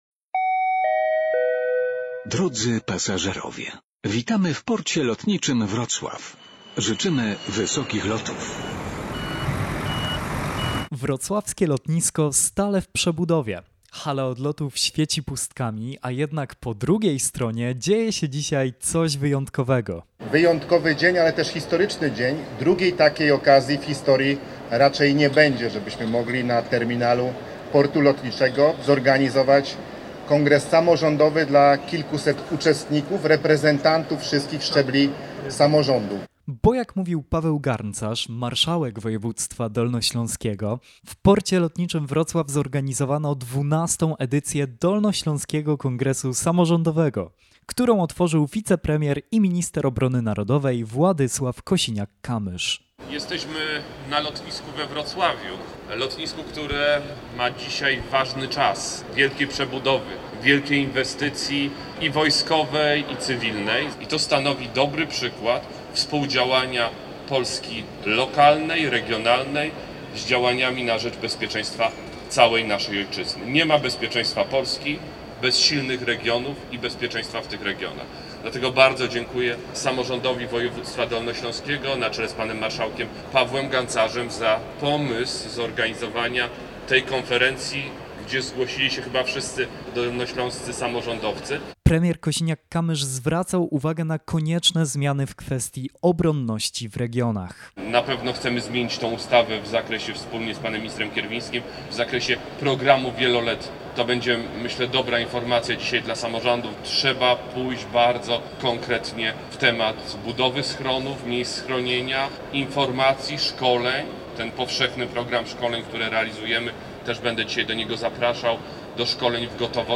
Zazwyczaj słychać tu komunikaty o odlotach i przylotach, tym razem jednak hala Portu Lotniczego Wrocław rozbrzmiewała głosami samorządowców.